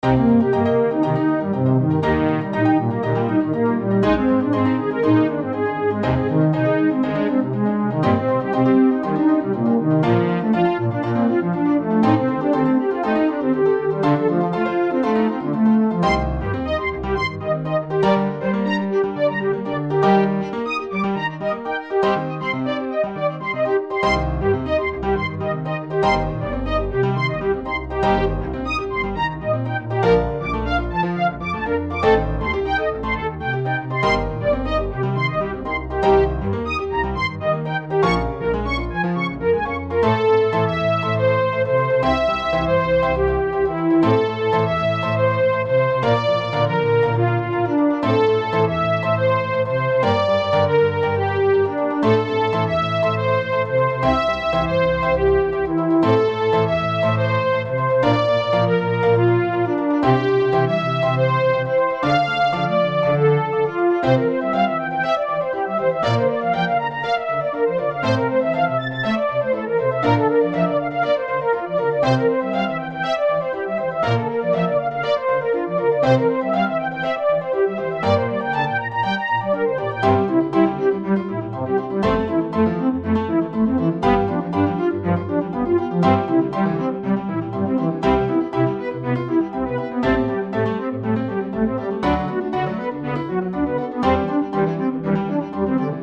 ソフトソロ、ピアノ、コントラバス